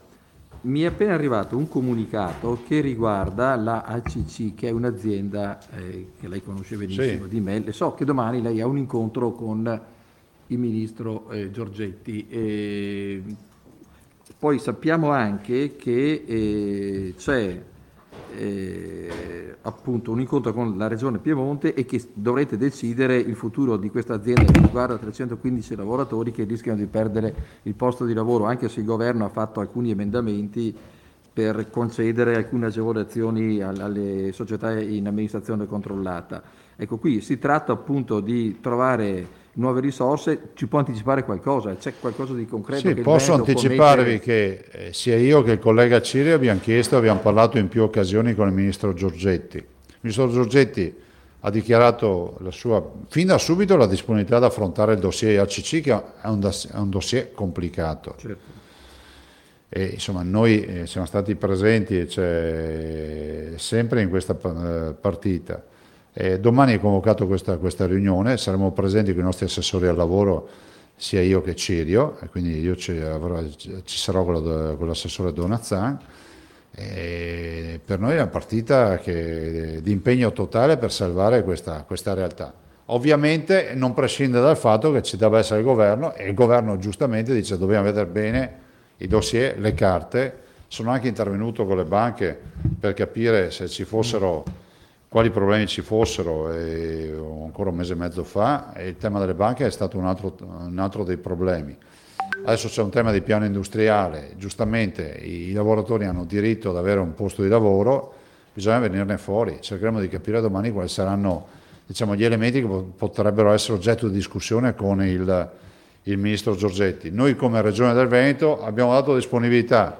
VENEZIA In conferenza stampa si è parlato di Acc alla luce dell’incontro con il Ministro Girgetti e al confronto tra Governatori di Veneto e Piemonte, Zaia e Cirio. Ecco l’intervento di Zaia pungolato dai giornalisti.